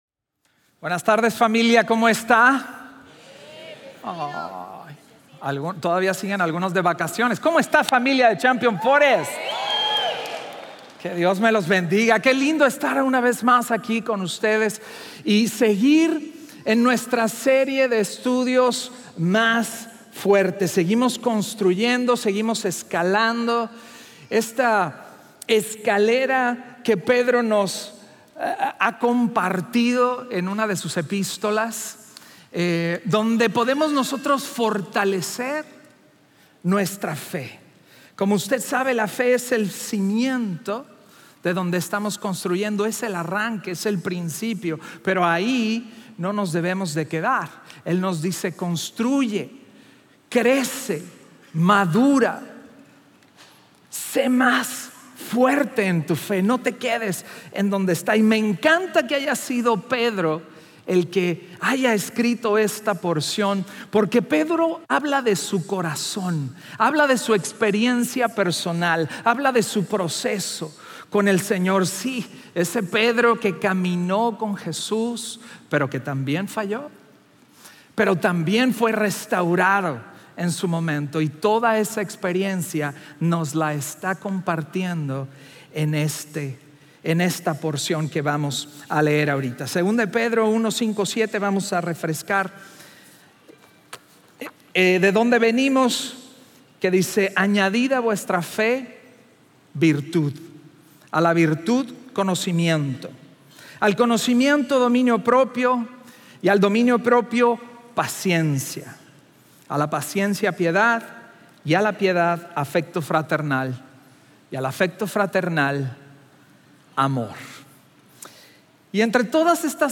Un mensaje de la serie "Decepción."